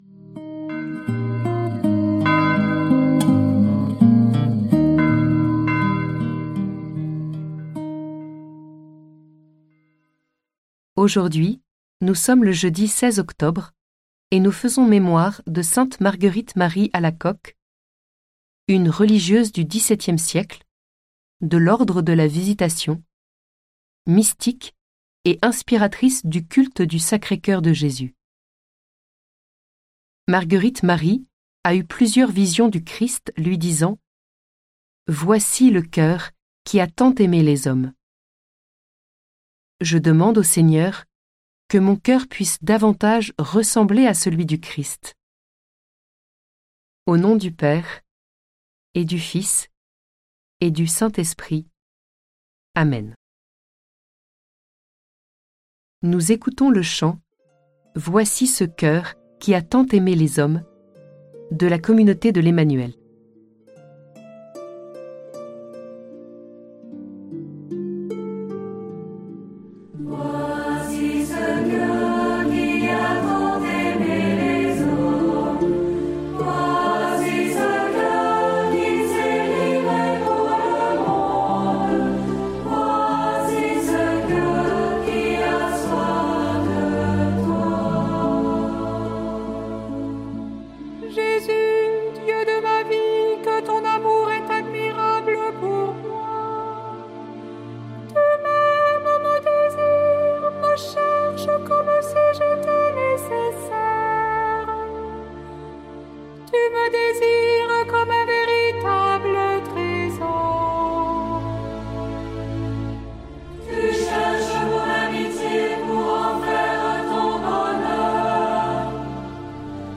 Prière audio avec l'évangile du jour - Prie en Chemin
Musiques